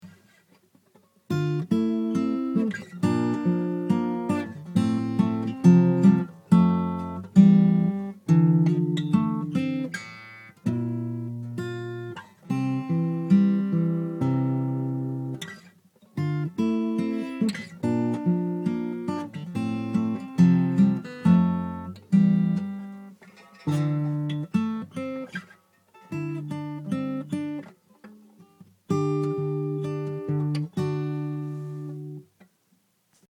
せめてギターをうまく弾けるようになろうと思って練習しました。
それでもっとうまくなってから今日のあまりうまく弾けてない様子を思い出したらたぶん「成長した。無駄ではなかった。」って思えるんじゃないかな。